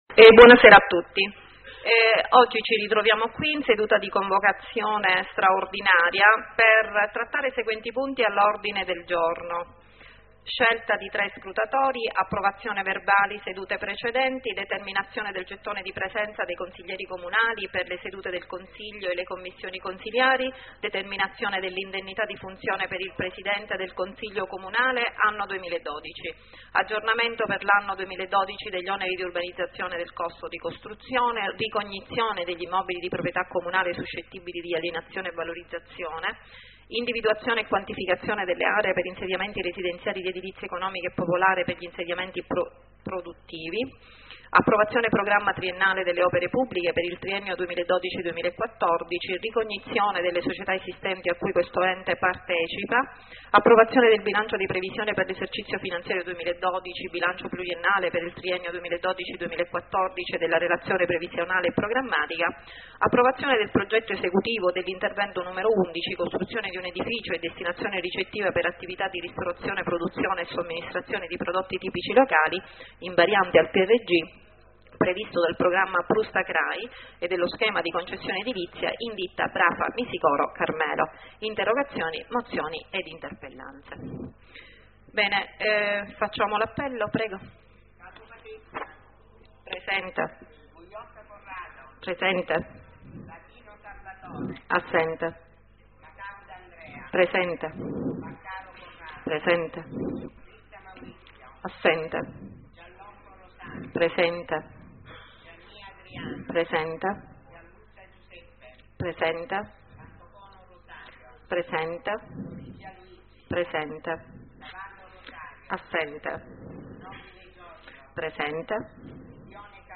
19 consiglio comunale 28.12.2012 .mp3 prima parte